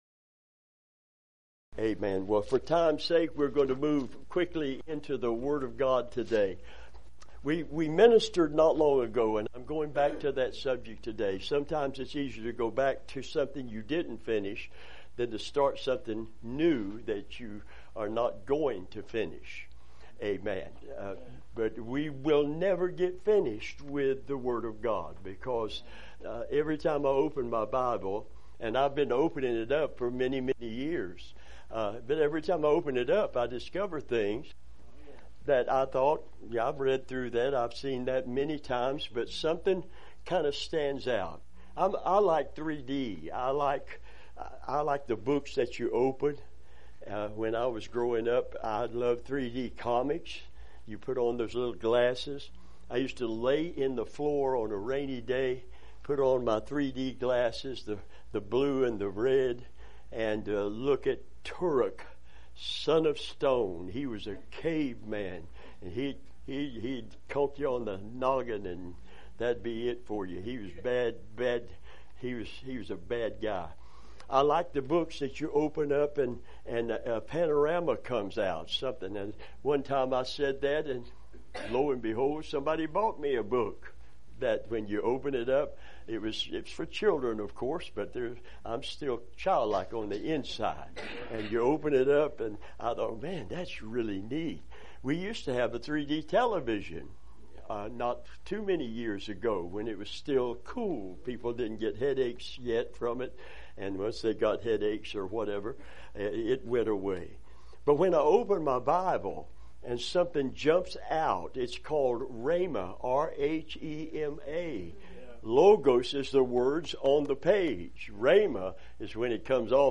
Revival Sermons